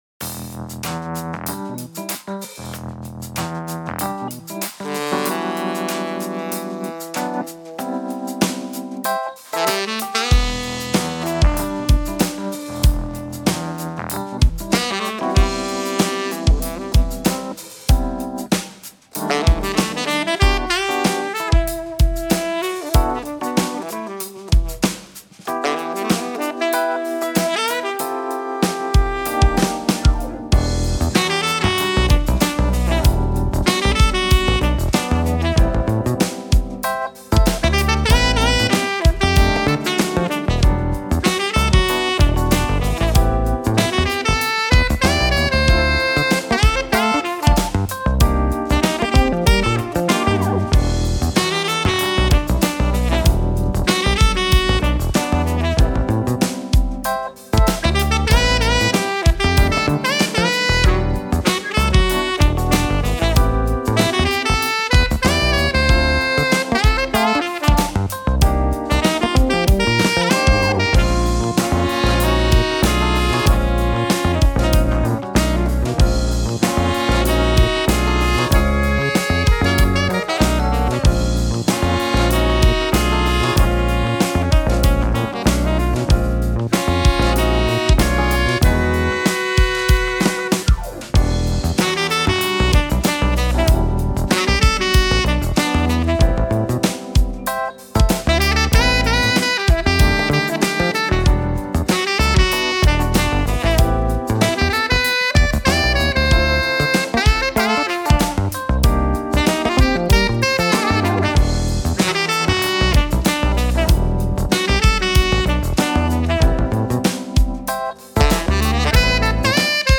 New smooth jazz song from our production!